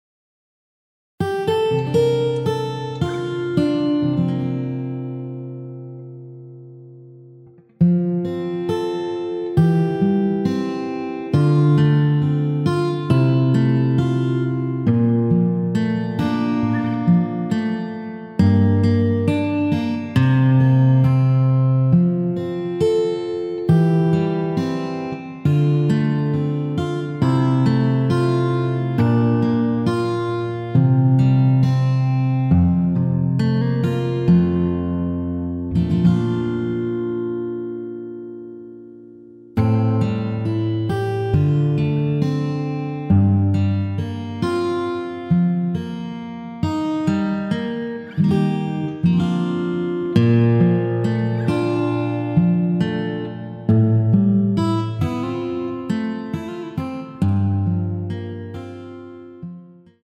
앞부분 “니가 어느날 ~ 오늘로 마지막이구나” 까지 없으며 간주도 4마디로 편곡 하였습니다.
앞부분30초, 뒷부분30초씩 편집해서 올려 드리고 있습니다.
중간에 음이 끈어지고 다시 나오는 이유는
축가 MR